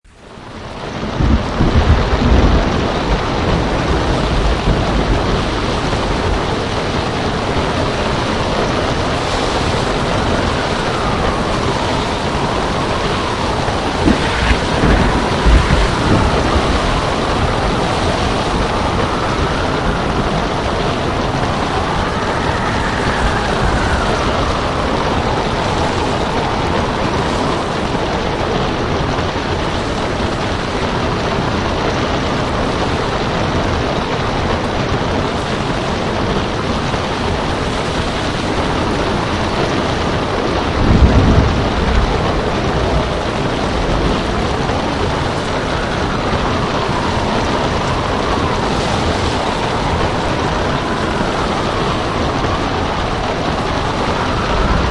Sound Effects
Ocean Storm